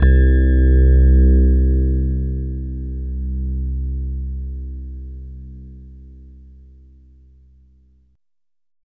20 RHOD C2-R.wav